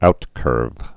(outkûrv)